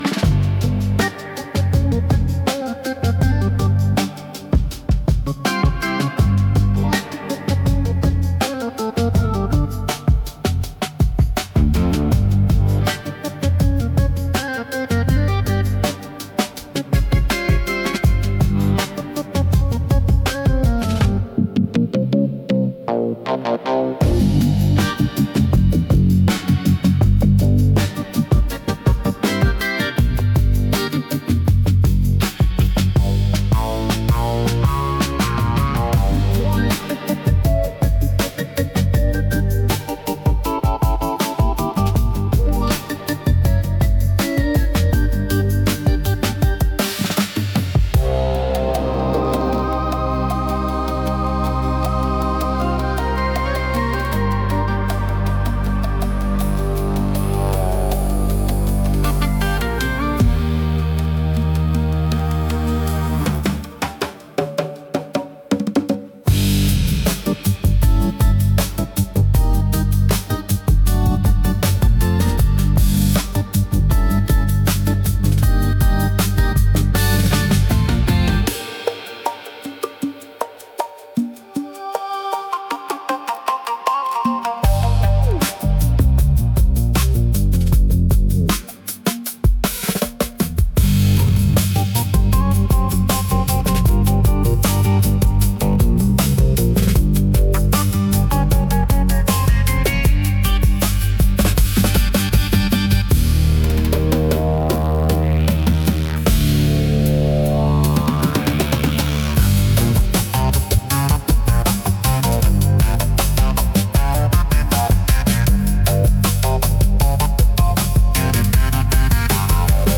イメージ：インスト,サイケデリック・ロック,オルガン
インストゥルメンタル（instrumental）